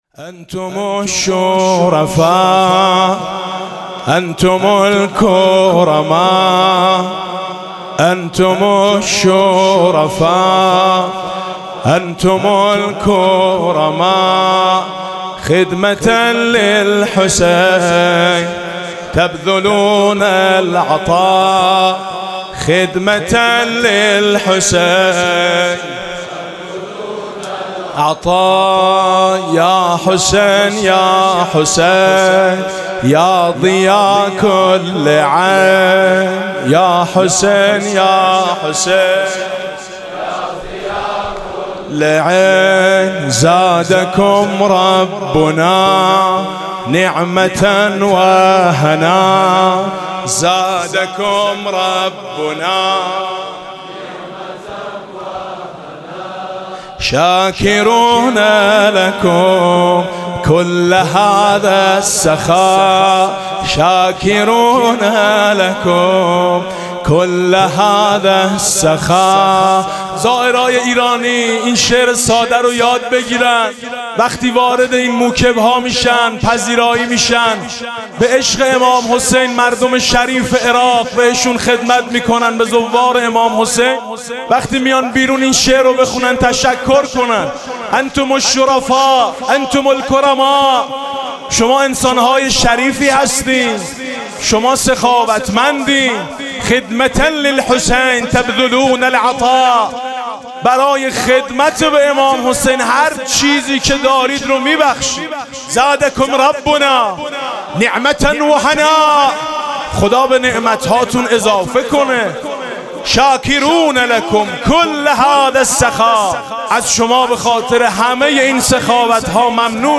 لطميات-الكوثر